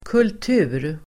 Uttal: [kult'u:r]
kultur.mp3